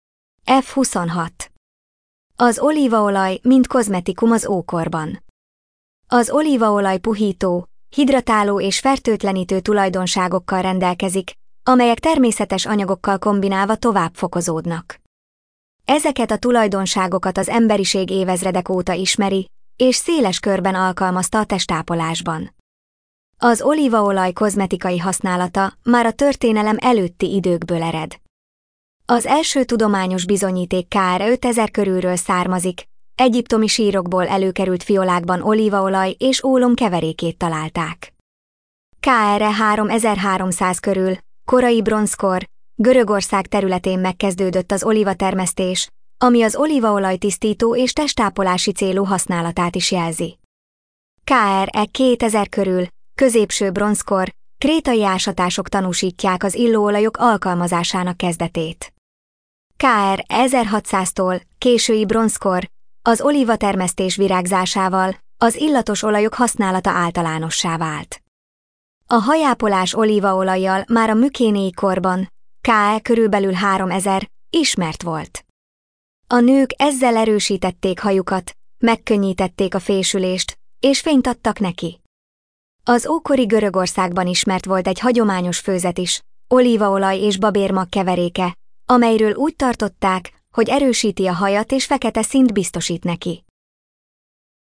Hangalapú idegenvezetés